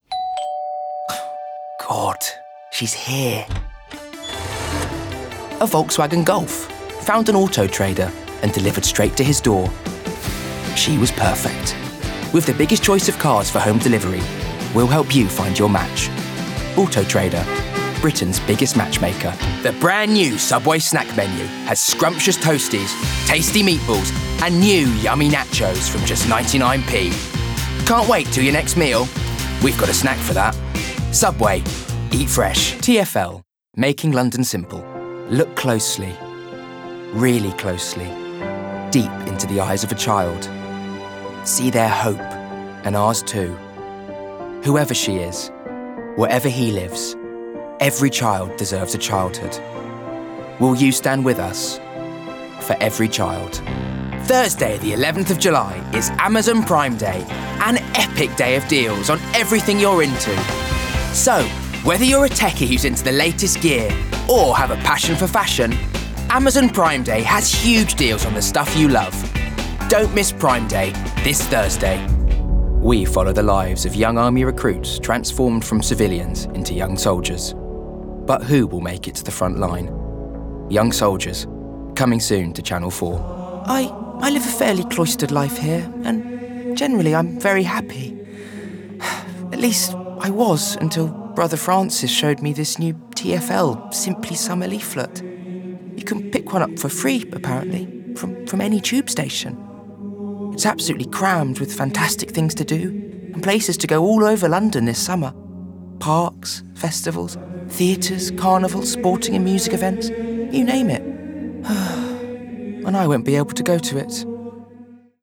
Commercial Showreel
RP ('Received Pronunciation'), Straight
Commercial, Showreel